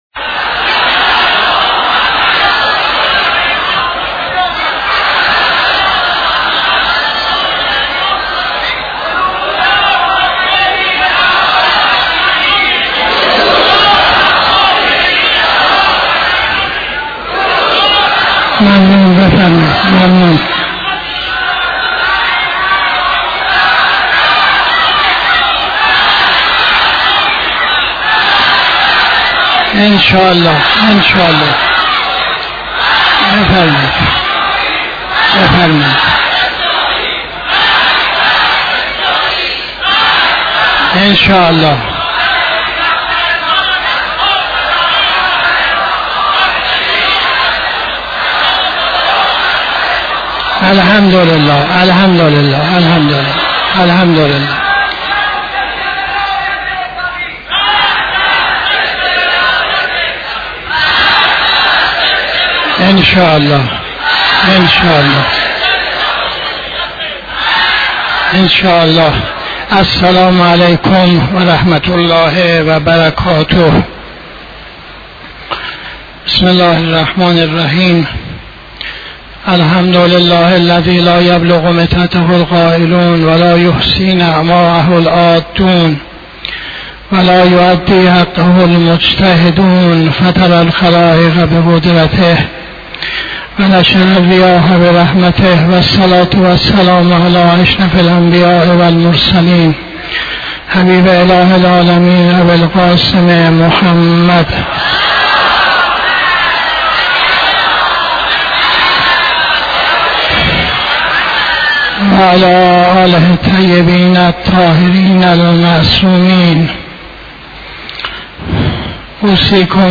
خطبه اول نماز جمعه 17-05-82